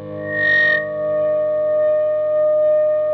PRS FBACK 7.wav